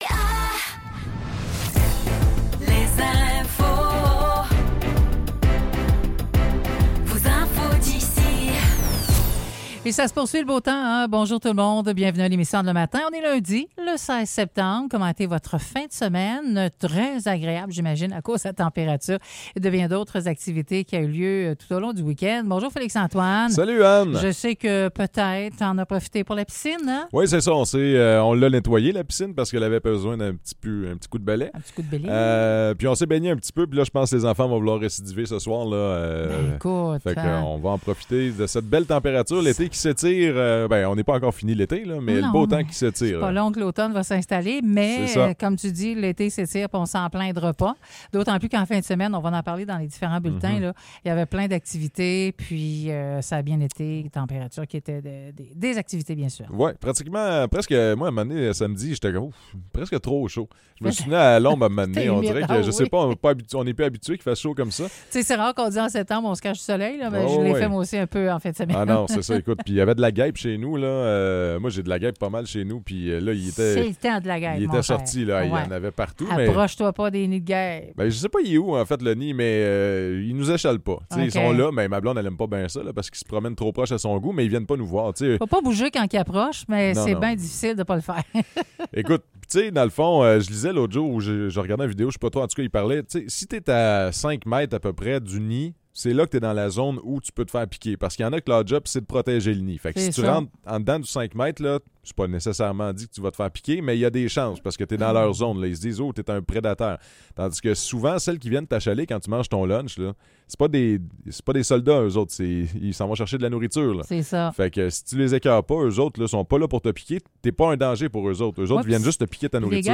Nouvelles locales - 16 septembre 2024 - 9 h